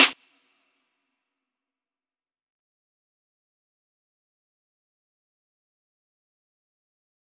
DMV3_Snare 1.wav